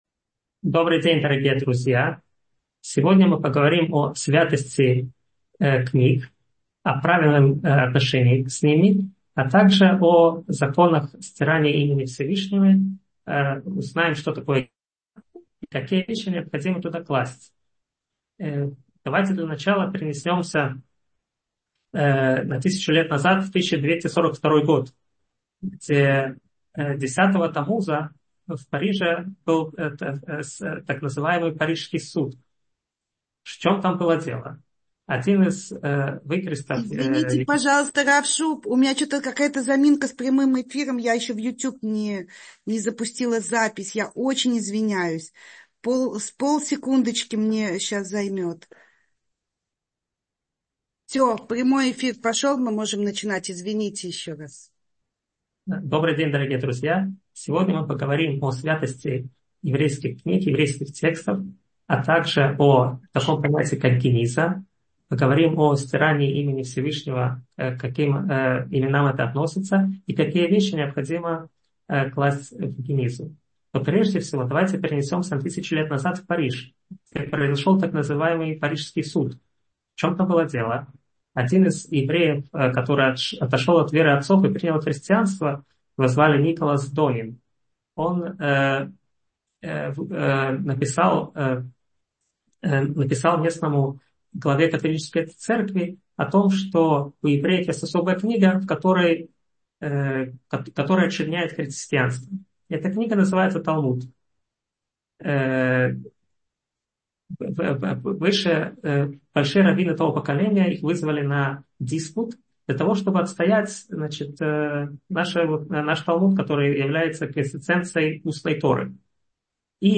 Правила обращения со святыми книгами и Именами Б-га — слушать лекции раввинов онлайн | Еврейские аудиоуроки по теме «Еврейские законы» на Толдот.ру